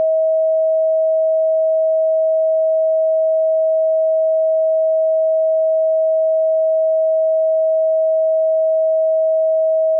As you immerse yourself in healing frequencies, such as
639 Hz.wav